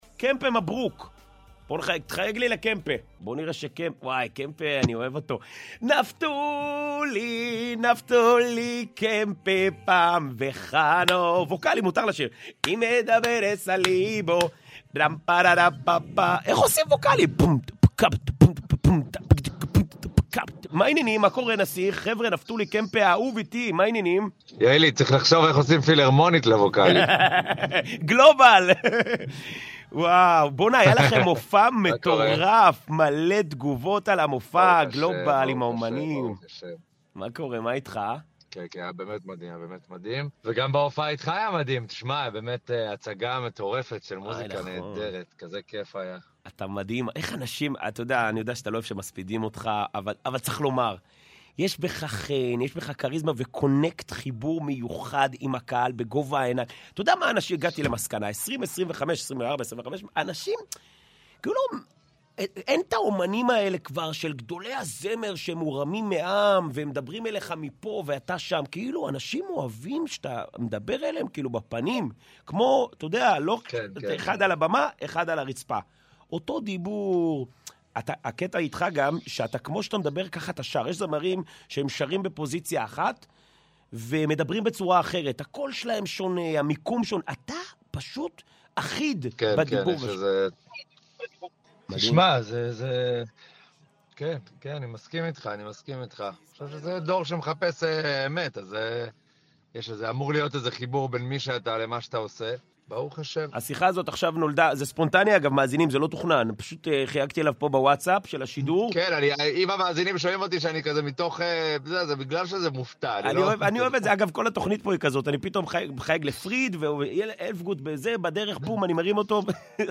ואם אפשר את הראיון מקול פליי להורדה אשמח https